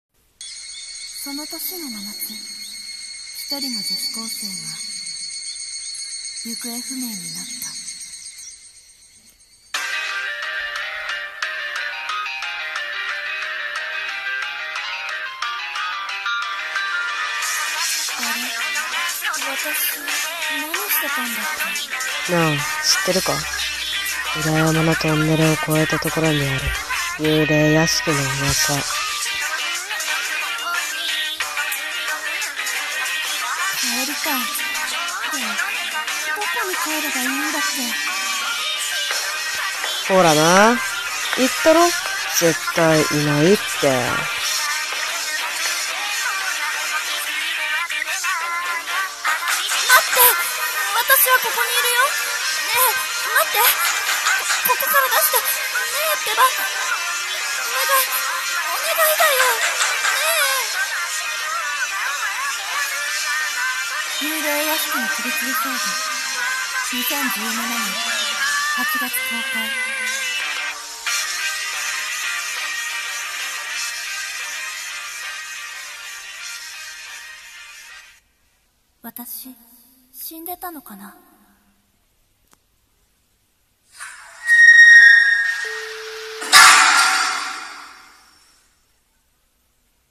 【声劇台本】偽映画予告CM 「幽霊屋敷の首吊り少女」